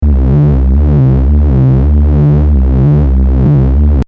experimental electronic, ambient,